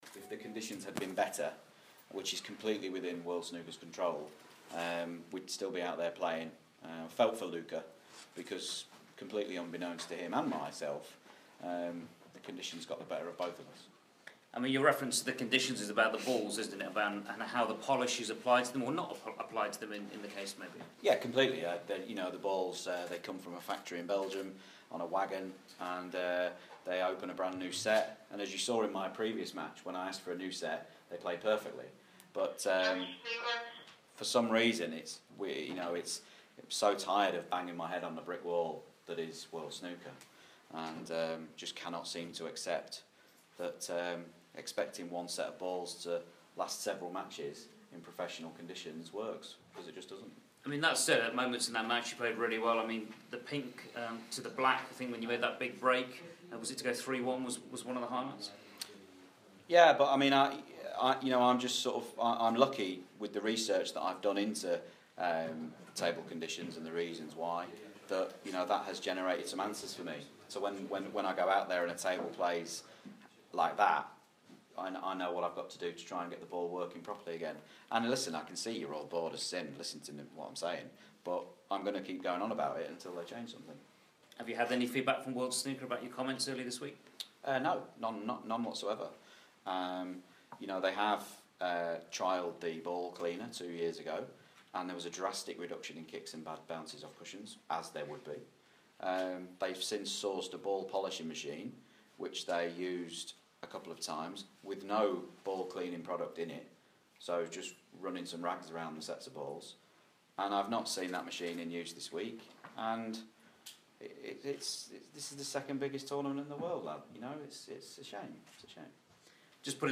After reaching the semi-finals of the UK Snooker Championship Shaun Murphy talks to the media